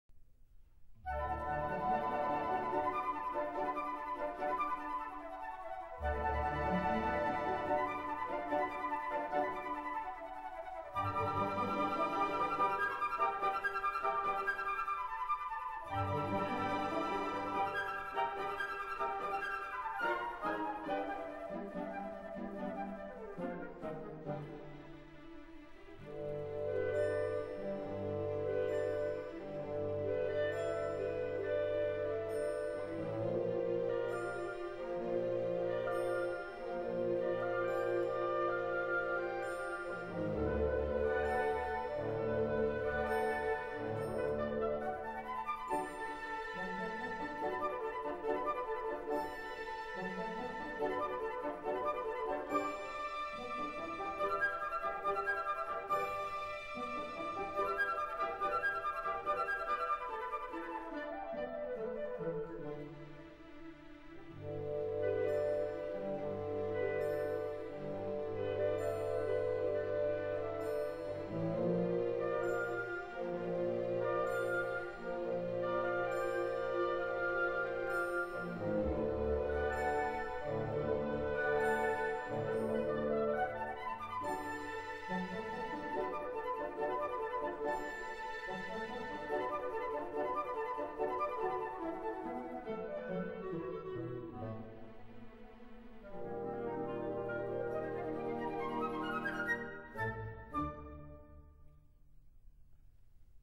按照大型管弦乐团的器乐编制以及演奏要求改编出这套《音乐会组曲》